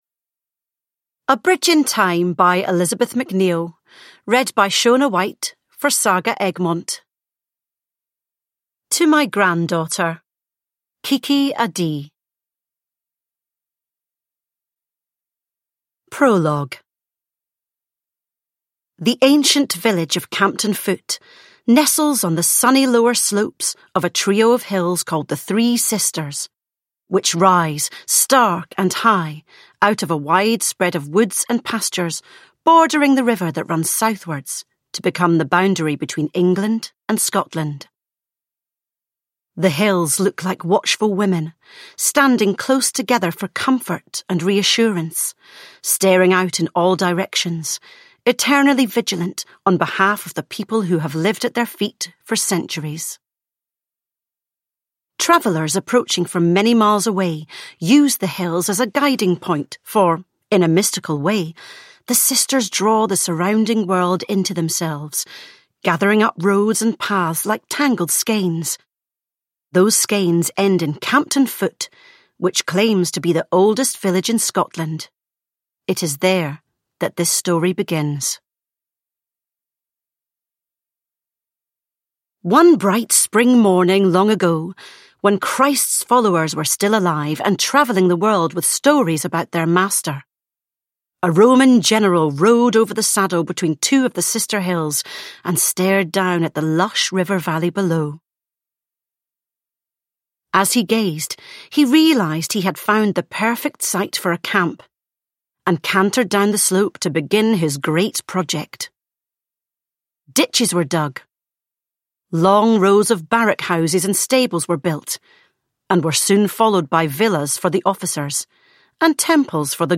A Bridge in Time: A moving Scottish historical saga – Ljudbok